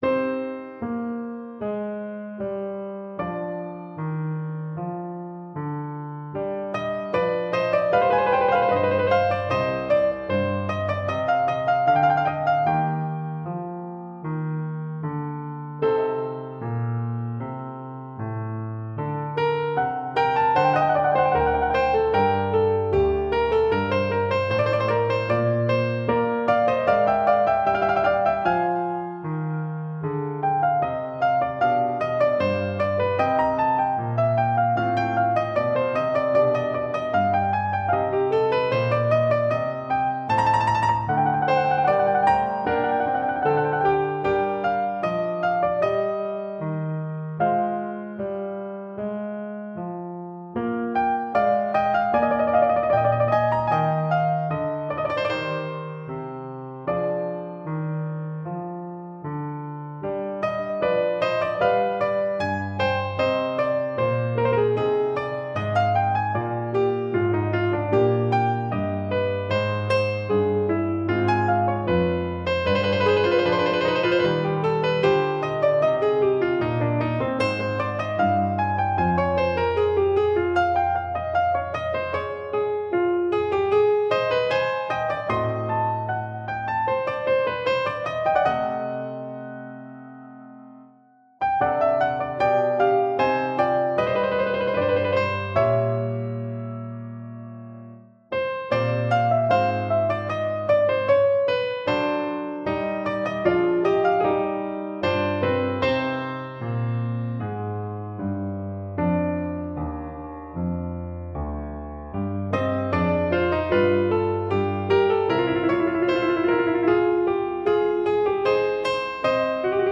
Oboe & String Quartet
» 442Hz